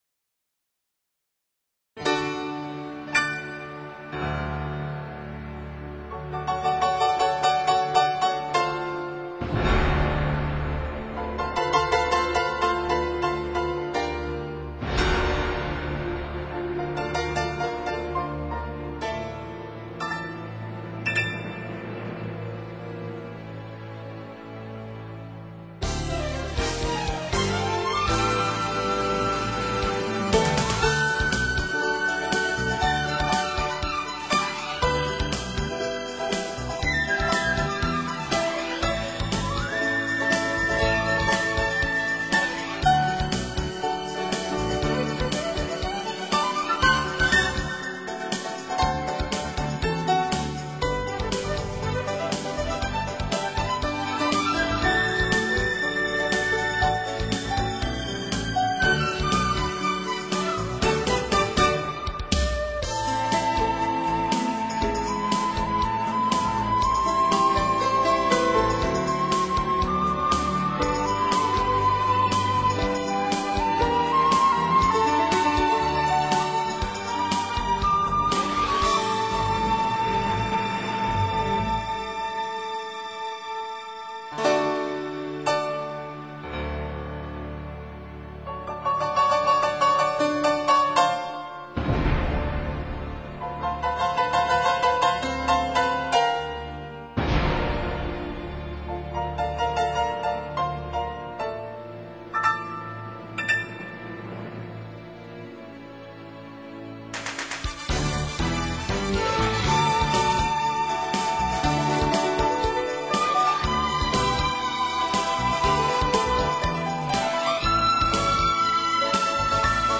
本专辑共耗费了120个小时录音时数，并搭配了75人编制的大型管弦乐团，合奏11首全新曲目，制作用心无以言喻。